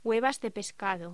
Locución: Huevas de pescado
voz